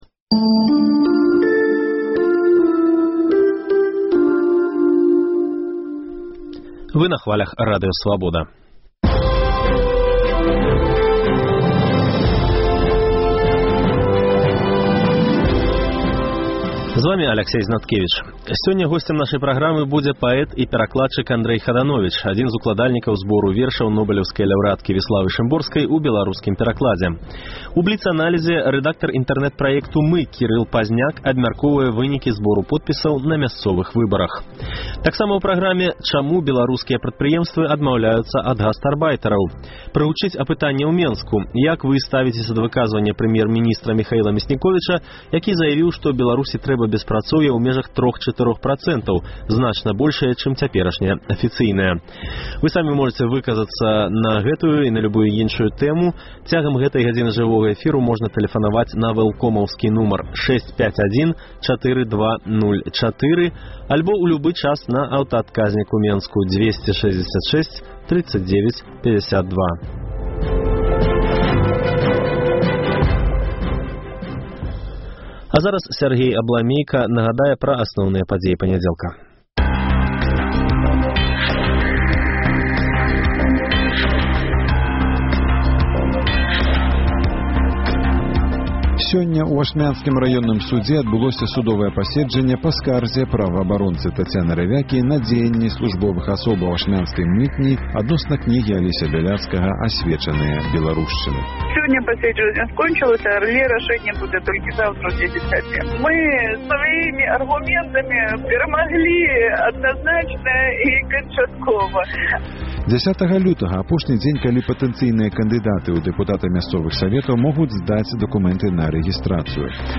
Хадановіч чытае Шымборскую